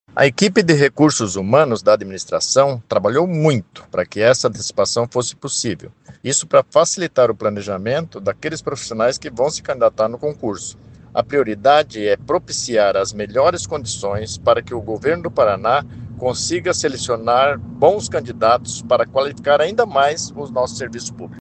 Sonora do secretário da Administração e da Previdência, Luizão Goulart, sobre a publicação do edital do concurso com 641 vagas para o Quadro Próprio da Saúde